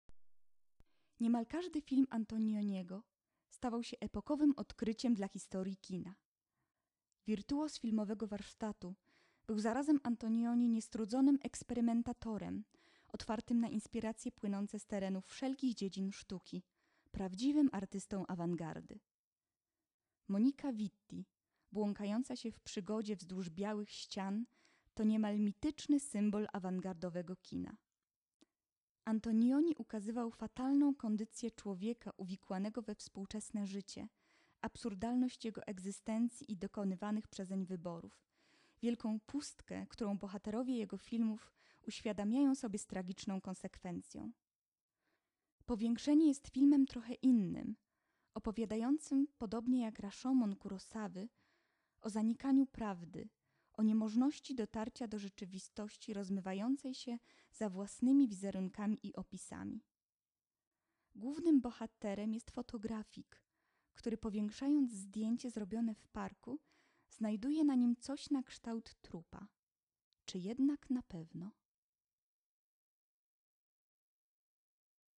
lektor018.wma